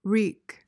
PRONUNCIATION: (reek) MEANING: verb tr.: To cause or inflict.